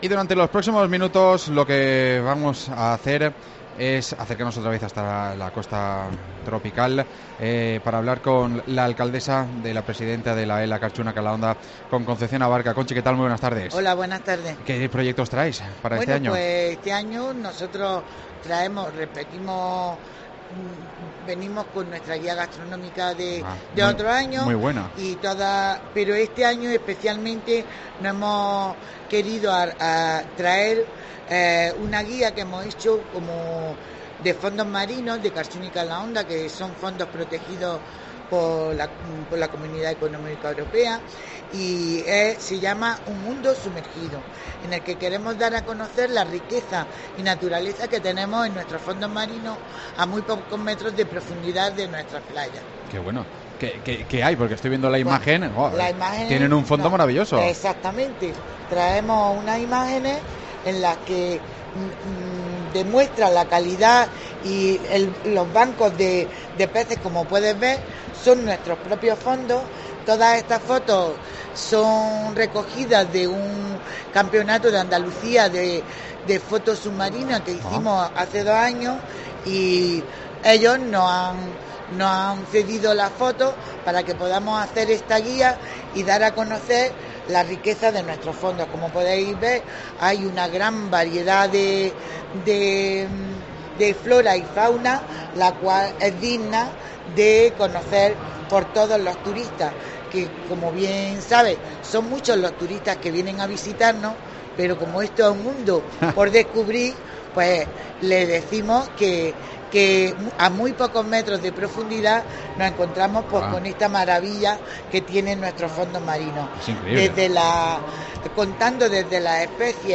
Conchi Abarca, la Presidenta de la Entidad Local Autónoma, nos cuenta en COPE cómo es la promoción turística de este año